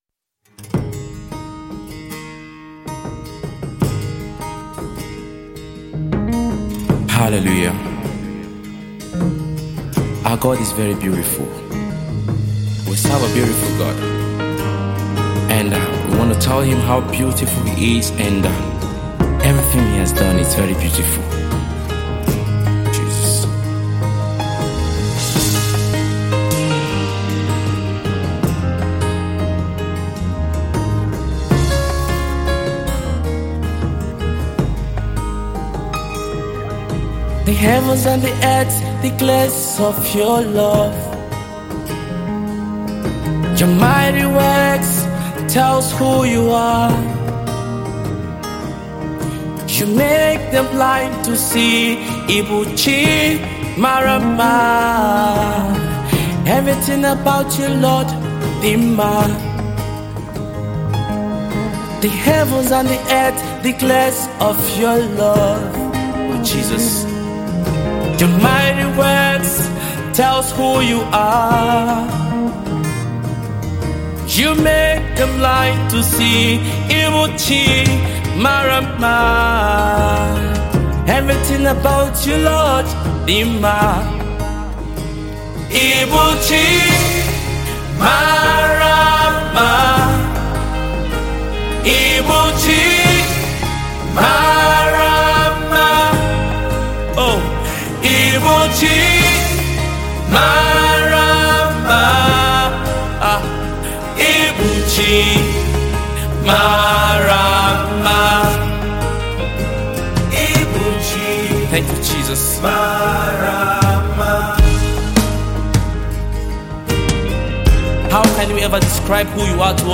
Nigerian gospel music minister